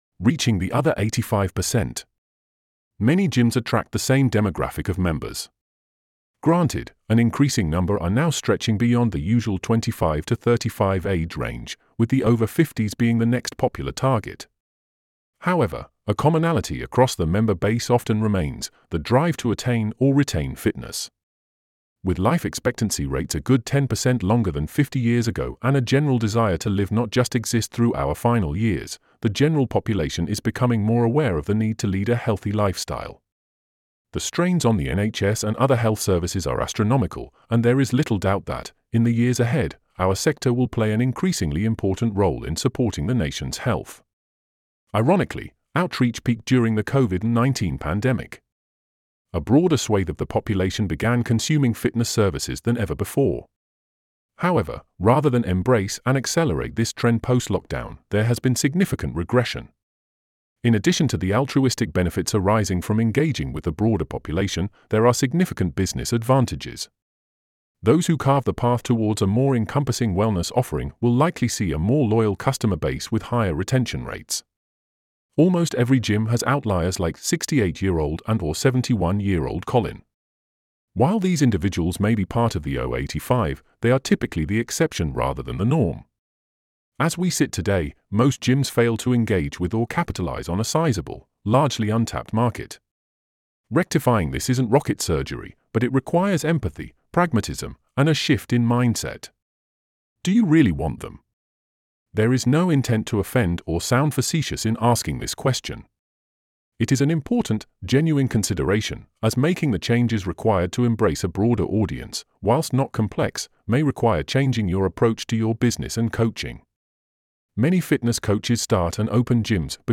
Alternatively, listen to the audio version of the booklet below.